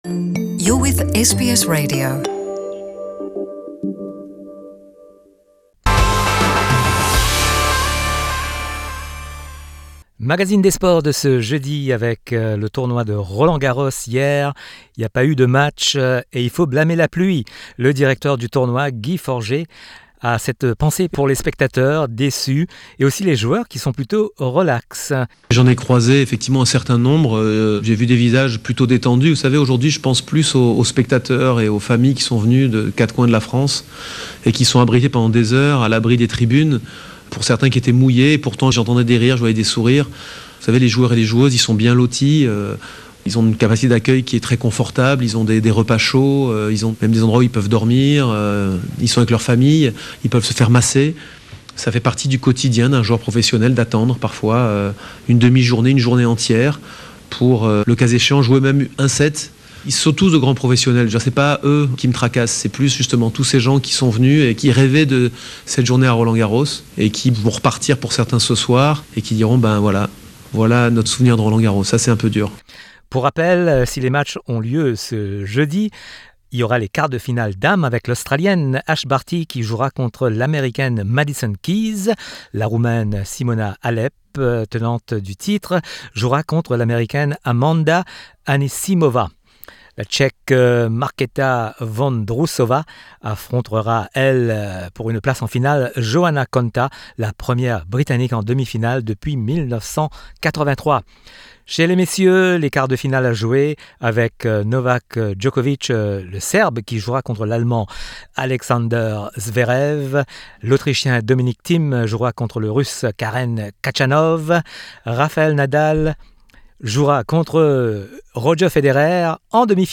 Le journal des sports du 06/06/2019